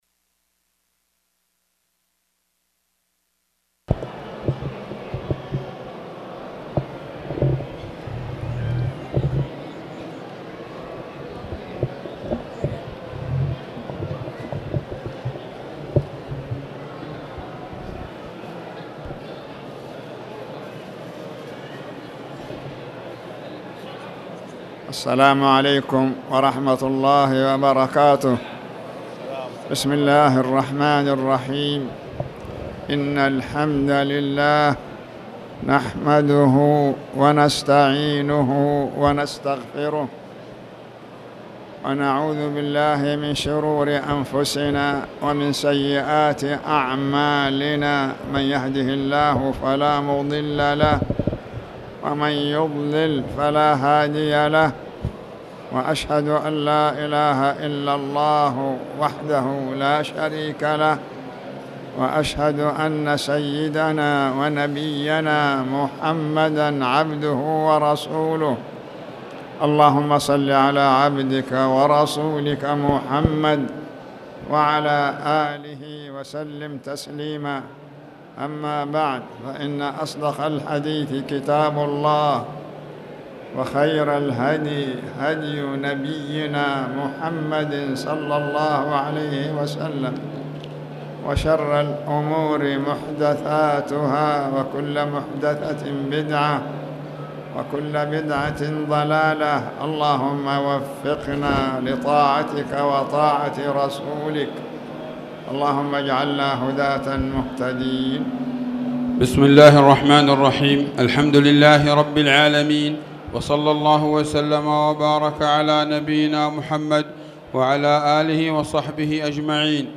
تاريخ النشر ١ جمادى الأولى ١٤٣٨ هـ المكان: المسجد الحرام الشيخ